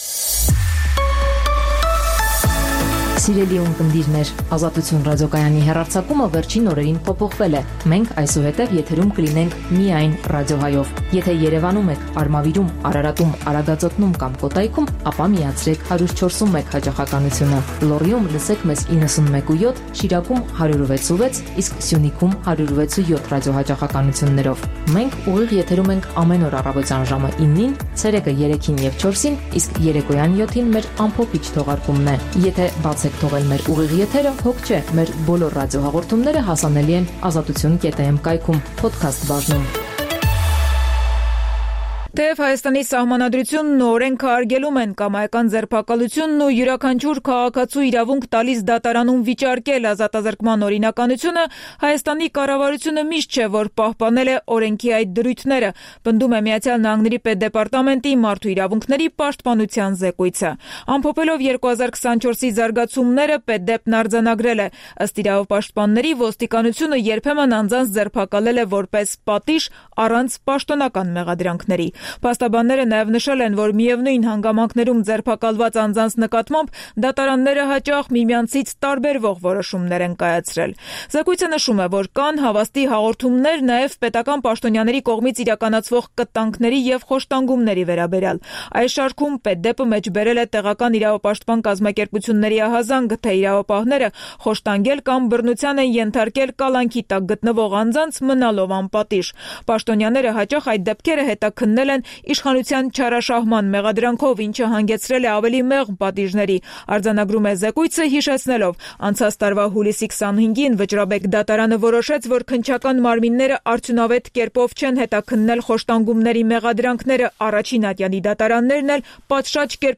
Տեղական եւ միջազգային լուրեր, ռեպորտաժներ, հարցազրույցներ, տեղեկատվություն օրվա սպասվող իրադարձությունների մասին, մամուլի համառոտ տեսություն: